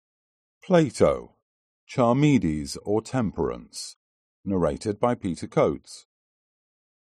Аудиокнига Charmides | Библиотека аудиокниг
Прослушать и бесплатно скачать фрагмент аудиокниги